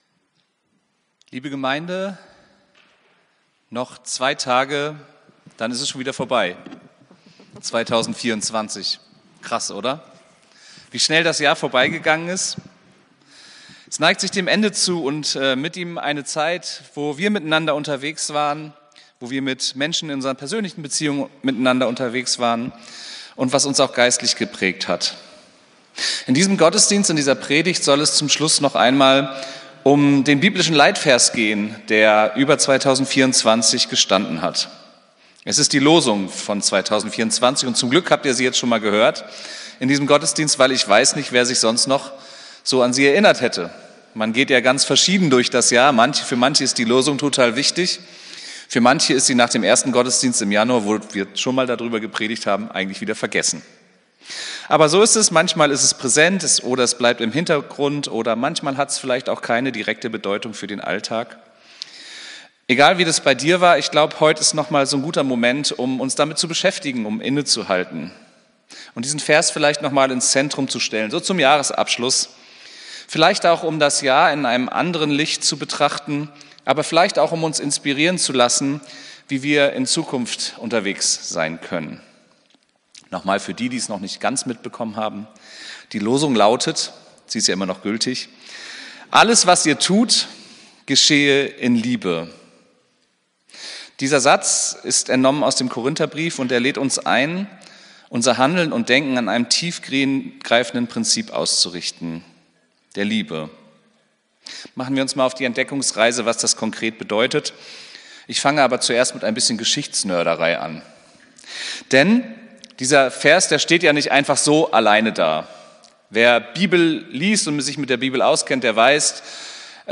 Predigt vom 29.12.2024